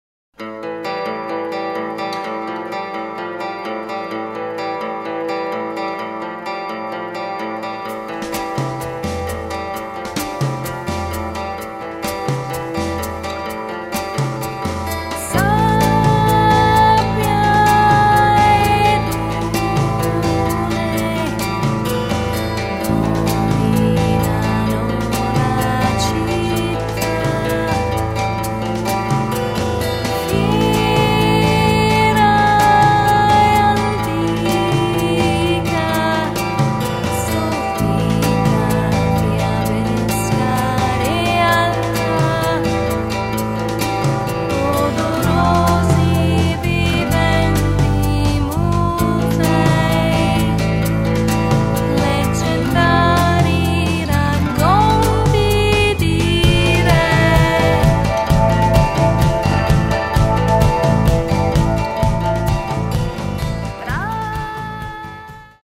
Il terzo esempio è riferito ad un mastering su un mix finale di musica etno rock
Ascolto MIX